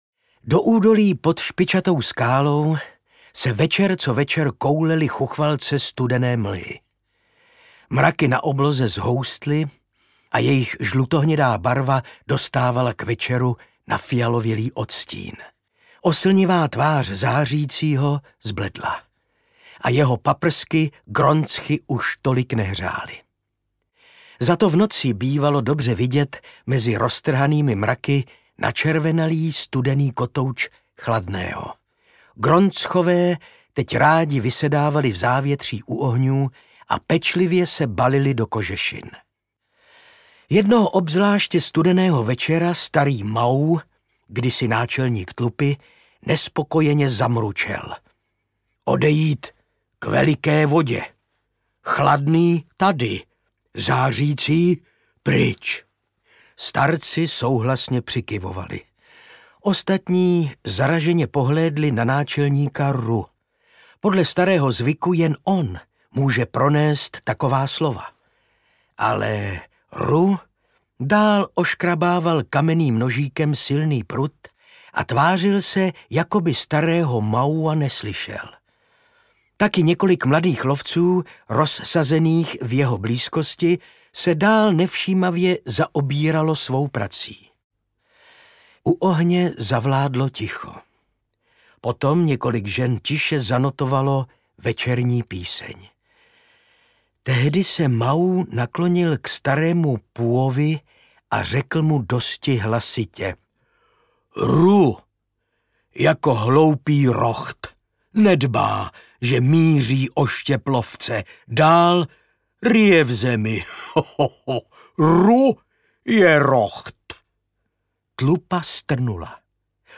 Příchod bohů audiokniha
Ukázka z knihy
• InterpretIvan Řezáč